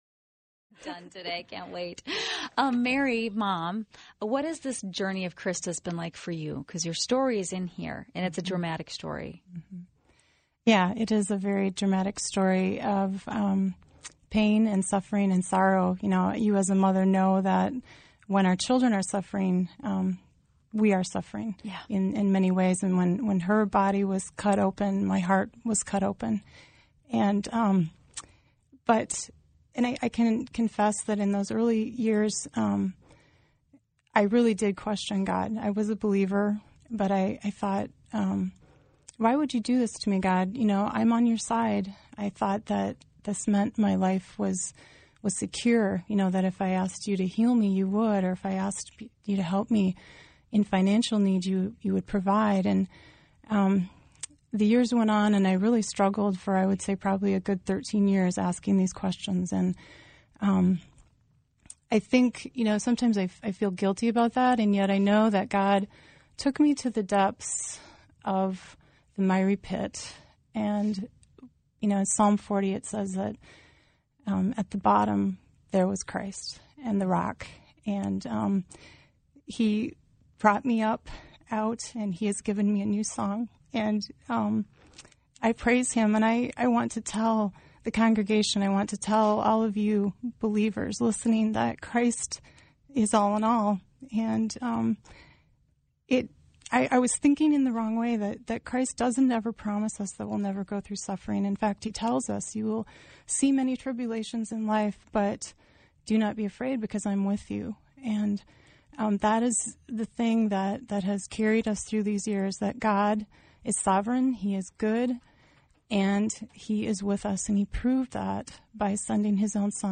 Again, thank you to KTIS and the Faith Radio network for making this interview freely available on their website.